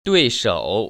[duìshŏu] 뚜이서우